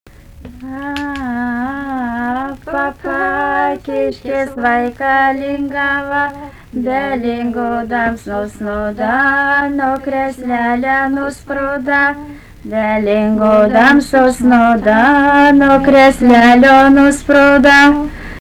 smulkieji žanrai
Kuršai
vokalinis
2 dainininkės